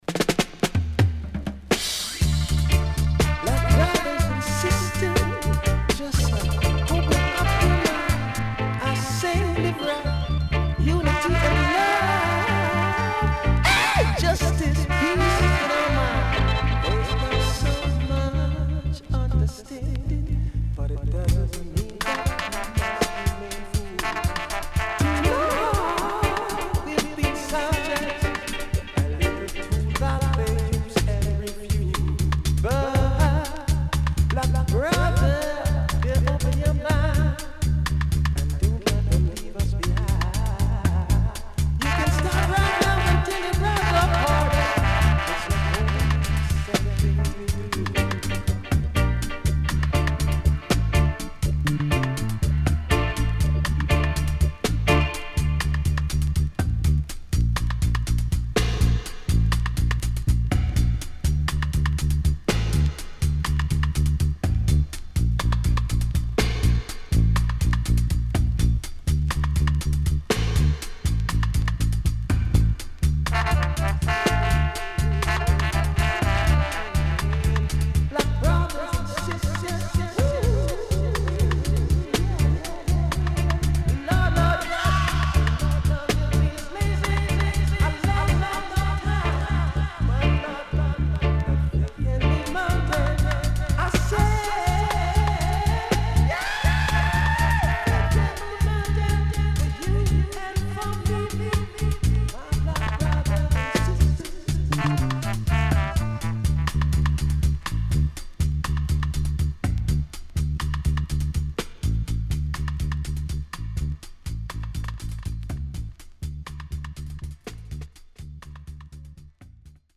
SIDE A:少しチリノイズ入ります。中盤あたりで音割れする部分あります(試聴で確認できます)。